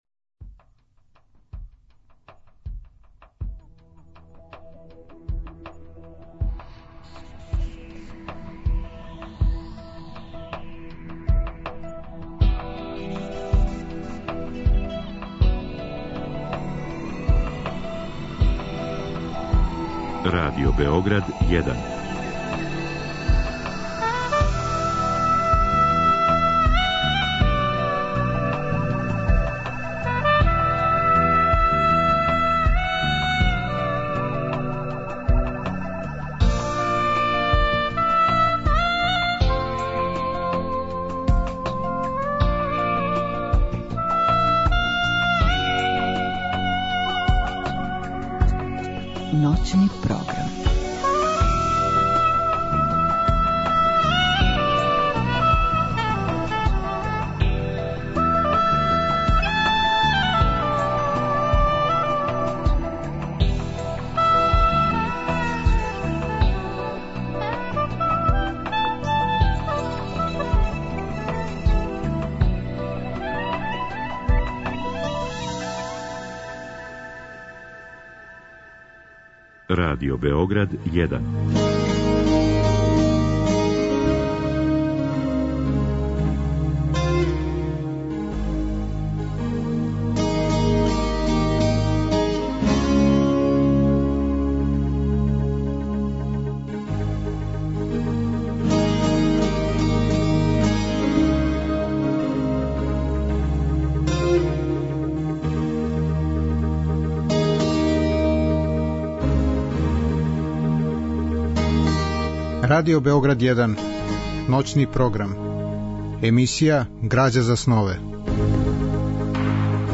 Разговор и добра музика требало би да кроз ову емисију и сами постану грађа за снове.
У трећем сау емисије слушаћемо песме Милоша Црњанског, у казивању самог аутора, а у четвртом сату емисије слушаћемо размишљања и сећања Милоша Црњанског, у којима велики писац говори о себи, свом животу и књижевном раду.